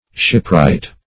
Shipwright \Ship"wright`\, n.